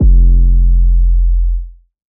SOUTHSIDE_808_punch_F.wav